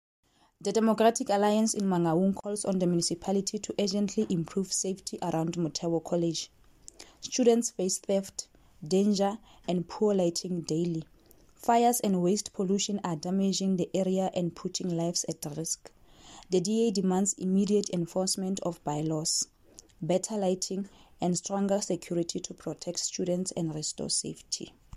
Sesotho soundbites by Cllr Lebohang Mohlamme.